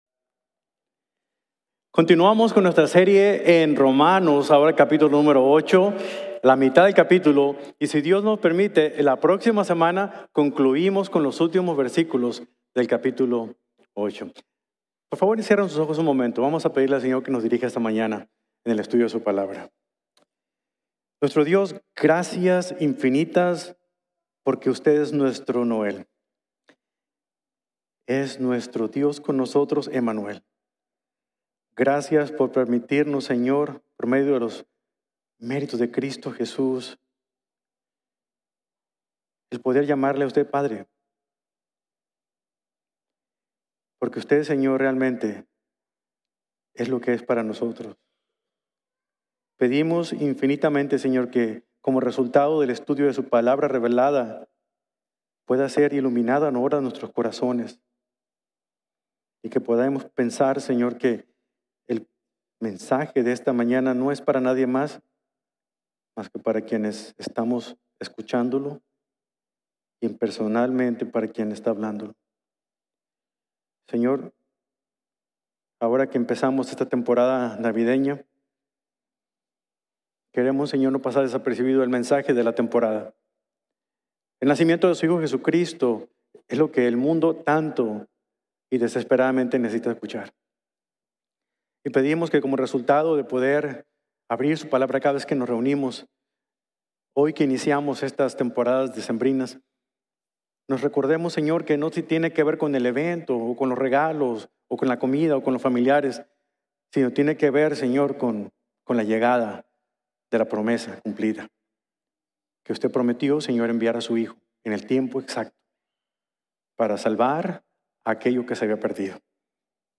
La Vida Guiada por el Espíritu | Sermon | Grace Bible Church